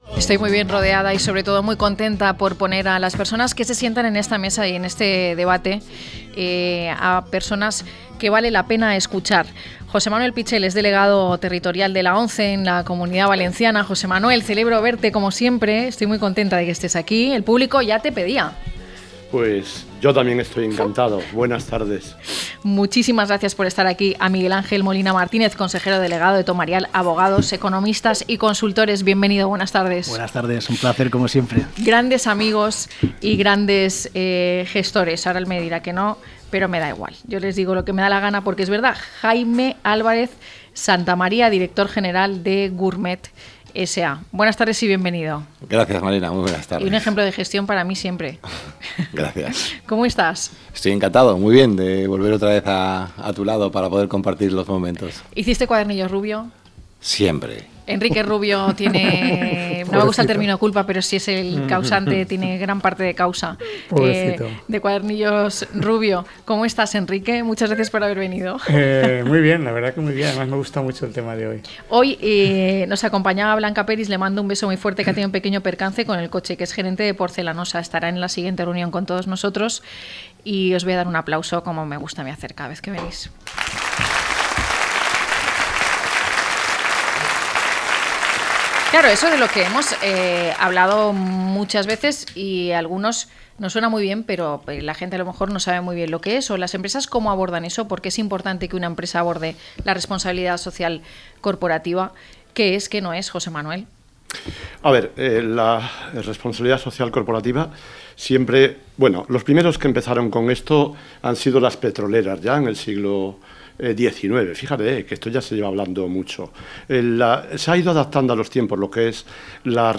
Nuestros contertulios exponen la visión financiera y legal sobre cómo asegurar la sostenibilidad de las empresas a largo plazo.
0930-LTCM-TERTULIA.mp3